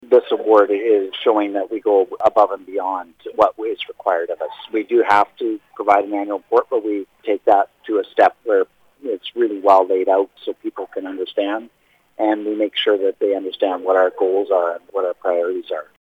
Mayor Phil Kent says it’s a great accomplishment.